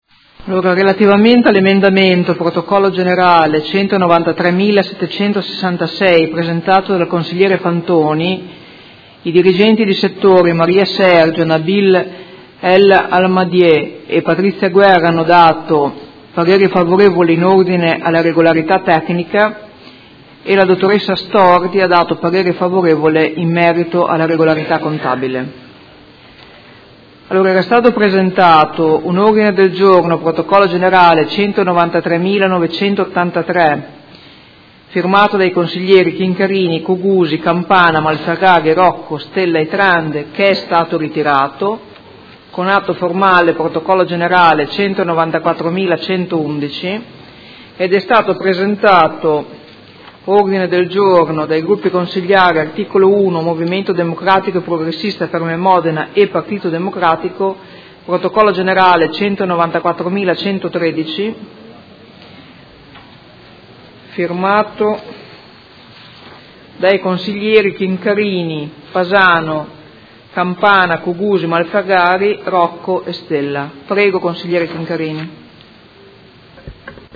Seduta del 21/12/2017. Comunicazione della Presidente su emendamento del Consigliere Fantoni Prot. Gen. 193766, su Ordine del Giorno Prot. Gen. 193983 che è stato ritirato